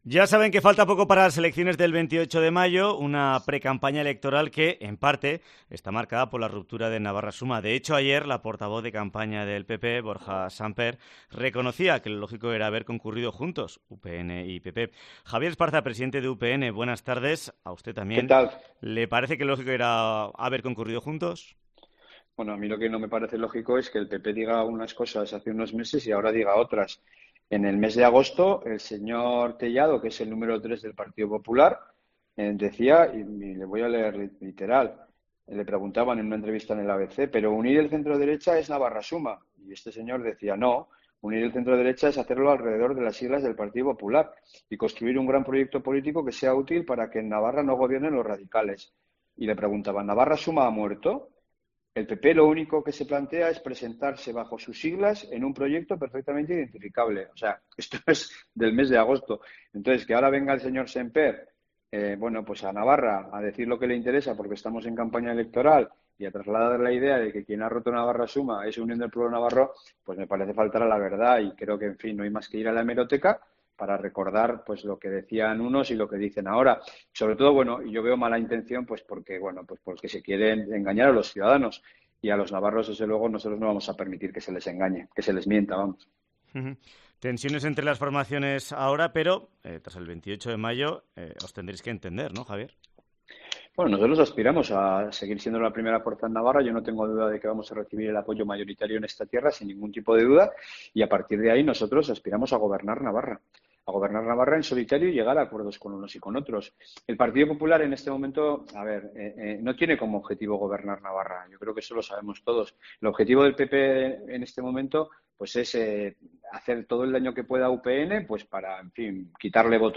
Entrevista con Javier Esparza, presidente de UPN